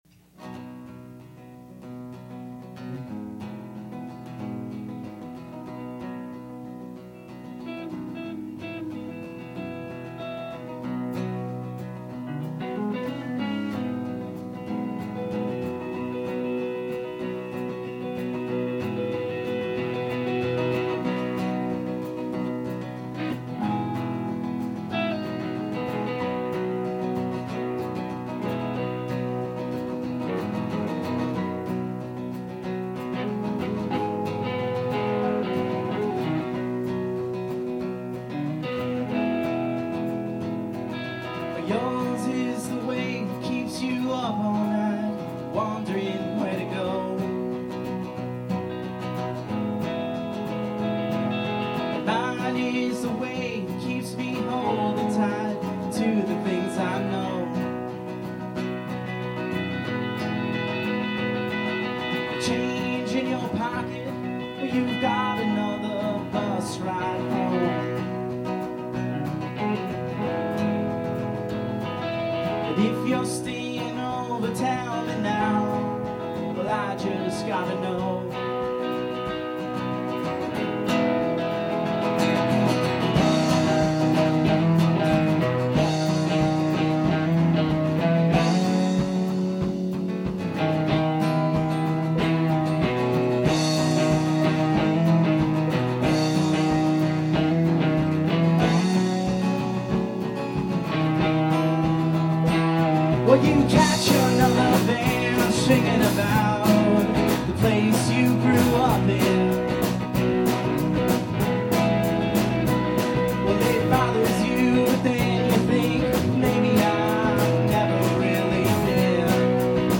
guitar, vocals
guitar, keys, vocals
bass, keys
drums